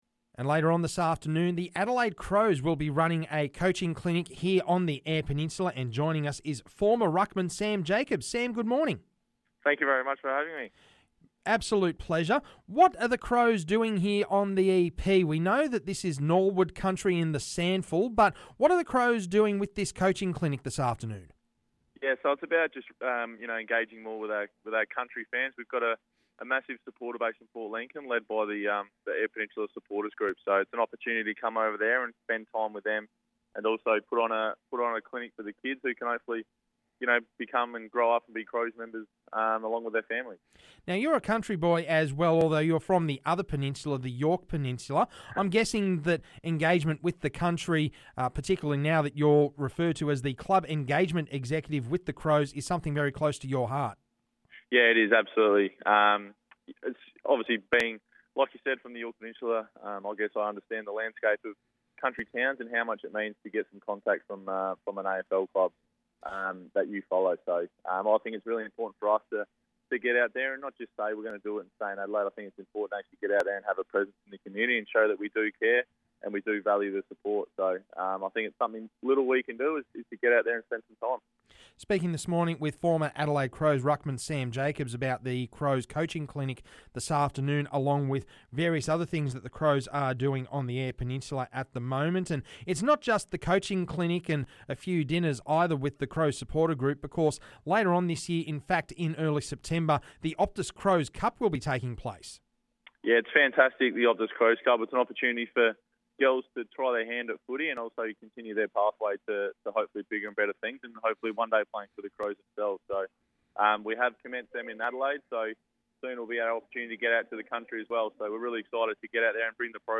Former ruckman Sam Jacobs joins us to discuss coaching clinics, women's footy, carnivals and what the Crows hope to bring to the EP.